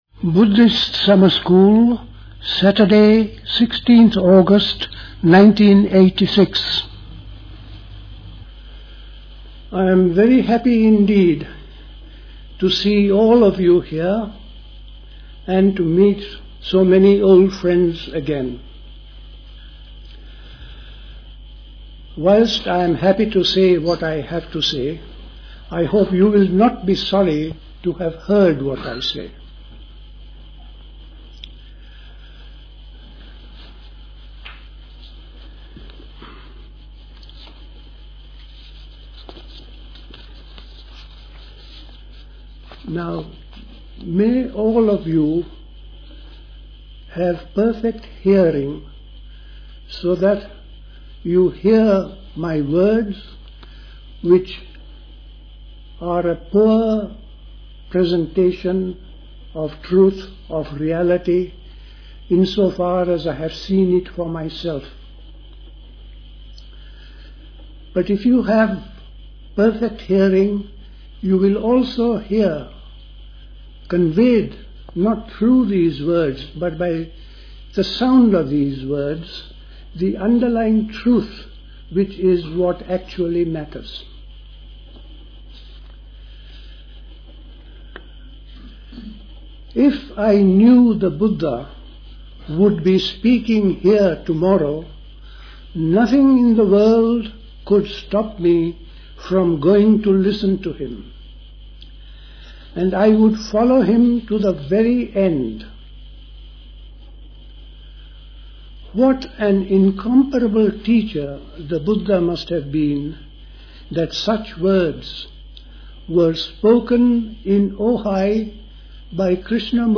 The Buddhist Society Summer School Talks